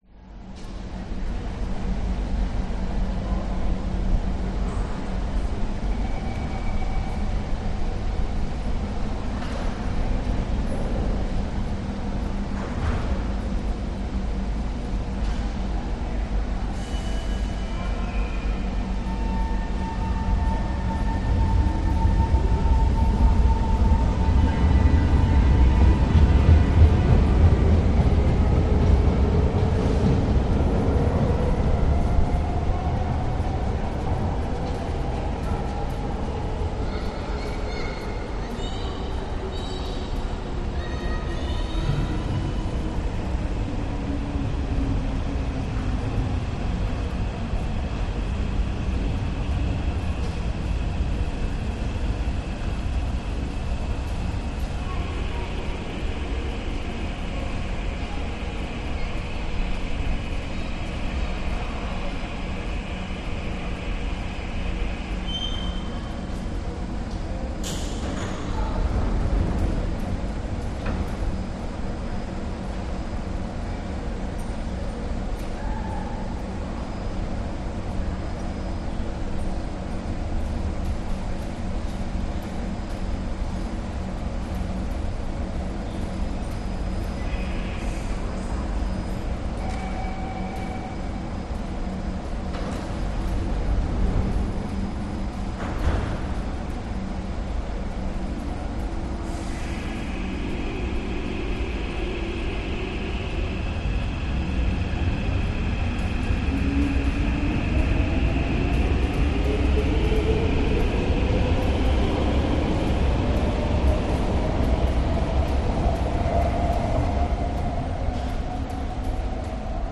Звуки жд вокзала
Погрузитесь в атмосферу железнодорожного вокзала с нашей коллекцией звуков: шум поездов, переговоры пассажиров, стук колес и электронные голоса диспетчеров.
Тихие звуки вокзала Kembangan MTR, редкие голоса, прибытие и отправление поездов, Сингапур